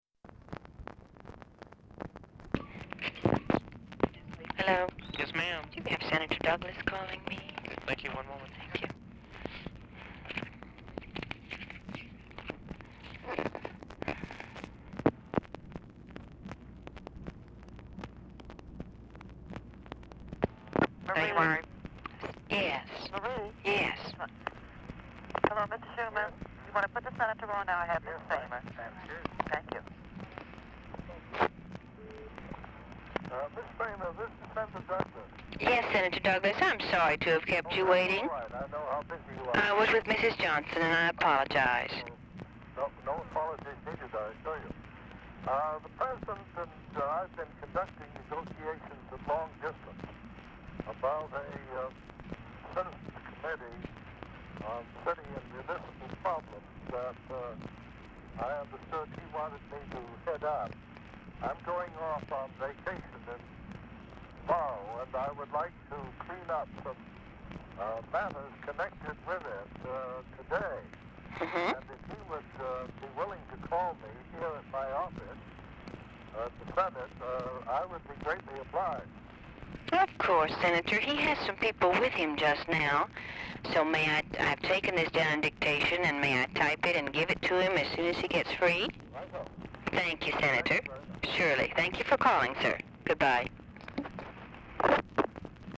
Telephone conversation
Format Dictation belt
Location Of Speaker 1 LBJ Ranch, near Stonewall, Texas